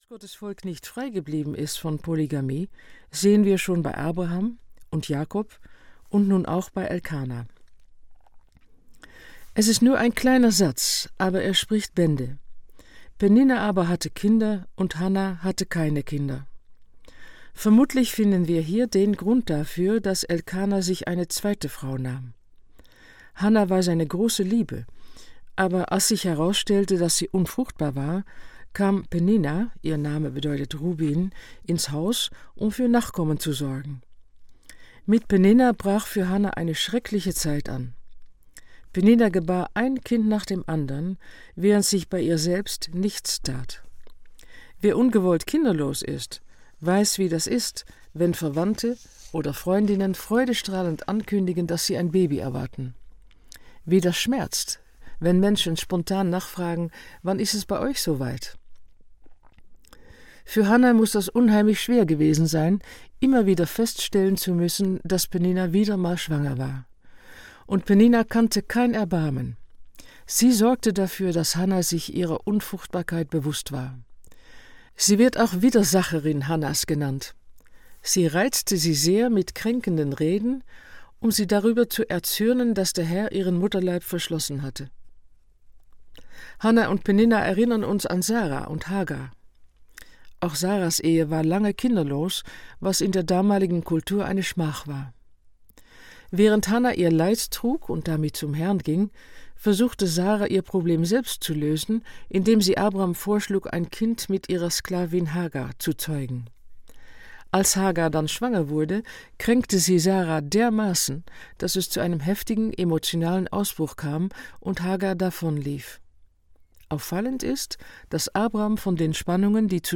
Samuel – von Gott erbeten - Noor van Haaften - Hörbuch